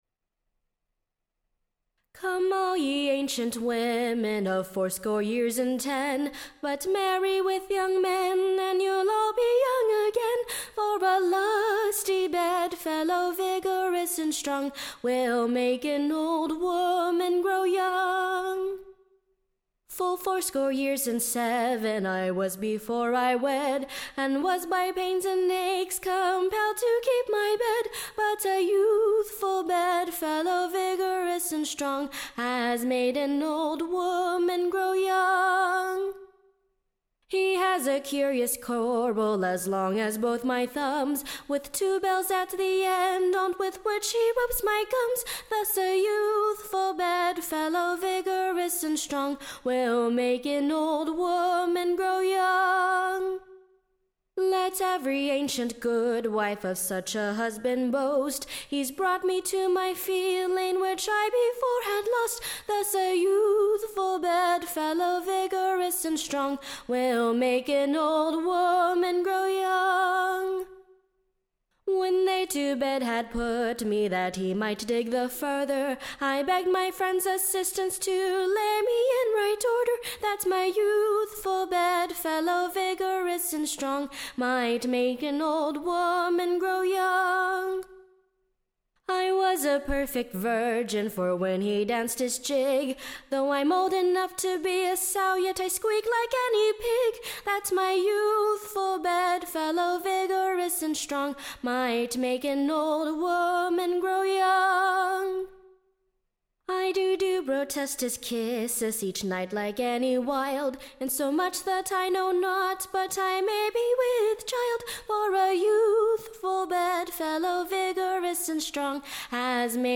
Recording Information Ballad Title Age renewed by wedlock; / OR, / The Old Womans Commendations of Her young Husband.